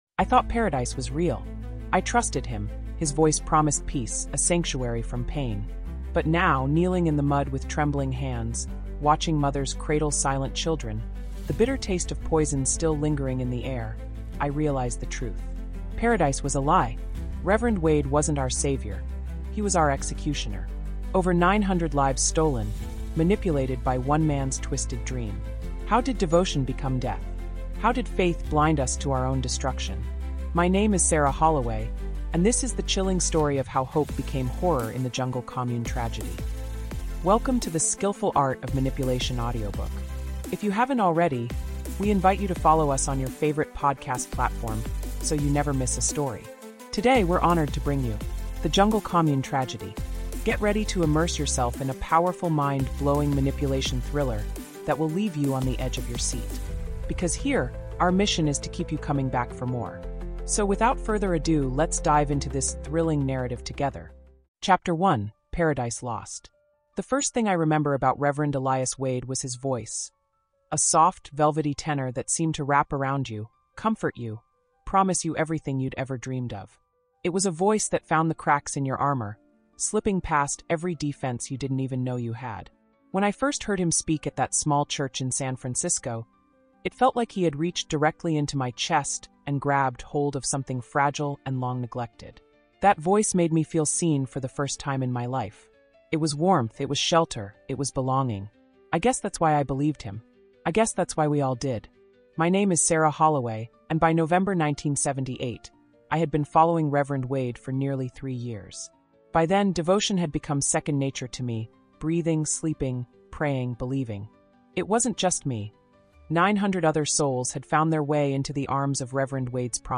The Jungle Commune Tragedy | Audiobook